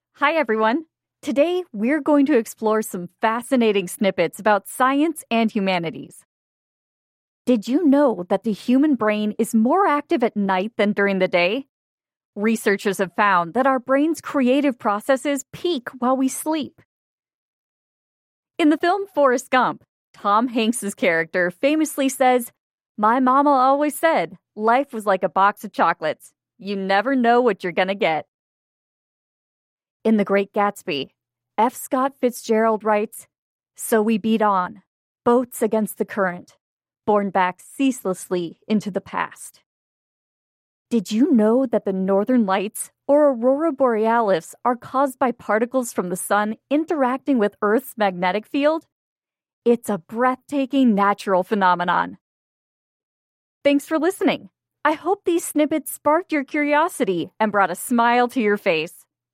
Where voices are not provided, as is typically the case for open source models, we use voices clips from professional voice actors as source files for generating speech.